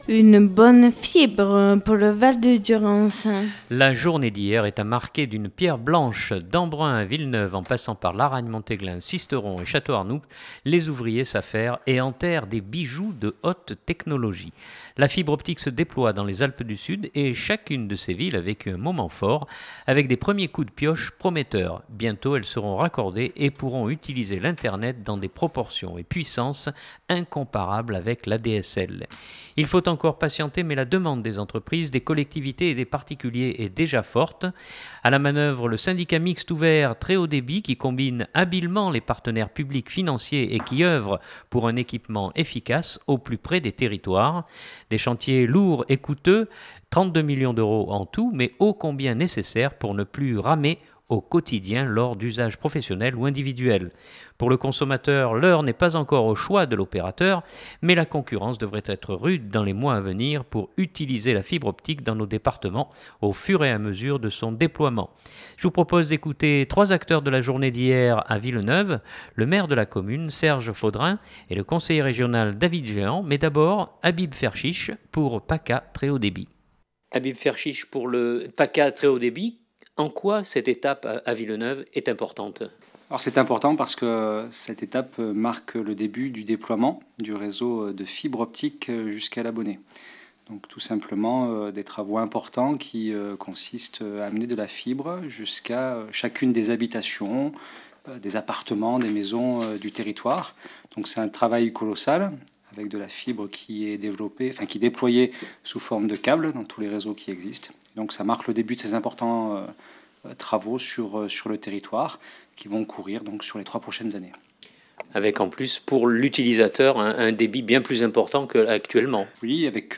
Je vous propose d’écouter trois acteurs de la journée d’hier à Villeneuve